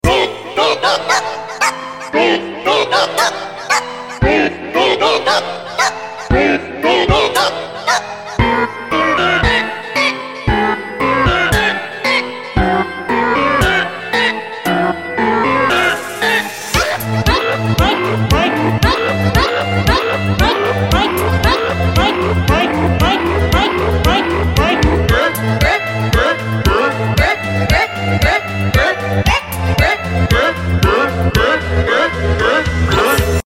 Remix (Battle Cover)